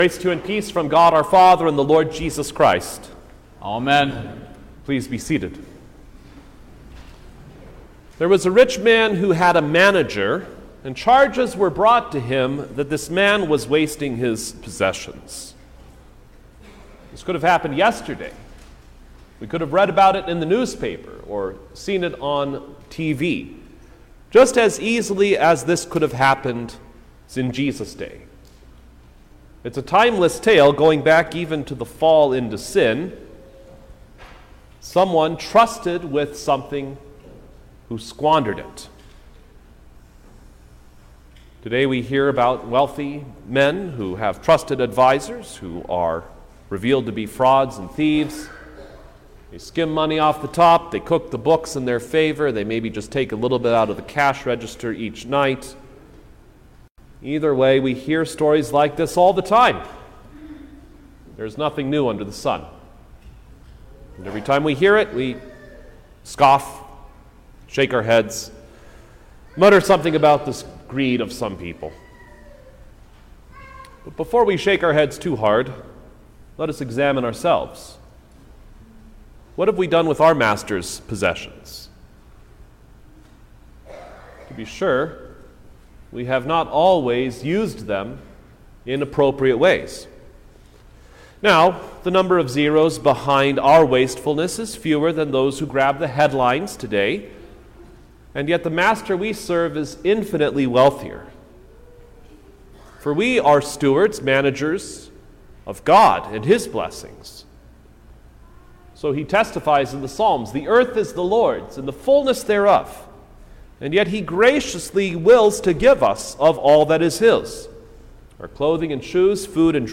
August-6_2023_Ninth-Sunday-after-Trinity_Sermon-Stereo.mp3